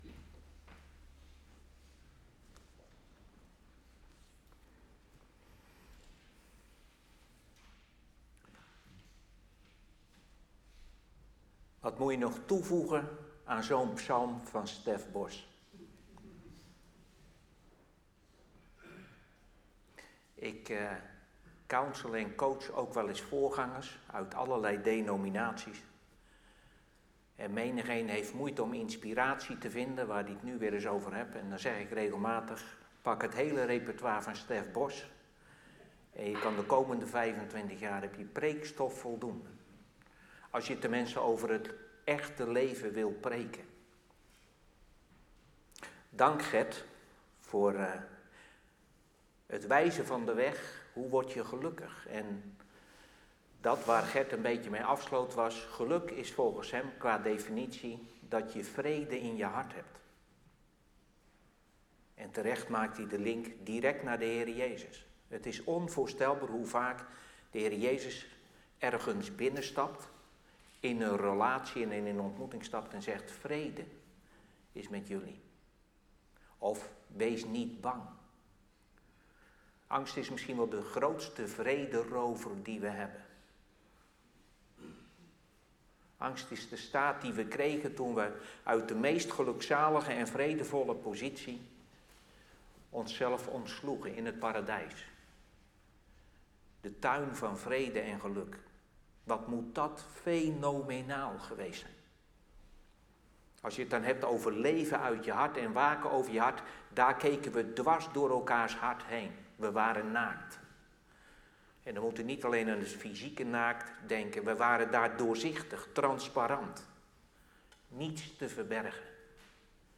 We mogen dankbaar terug zien op de Mannendag Delft die op 5 november in Delfgauw werd gehouden.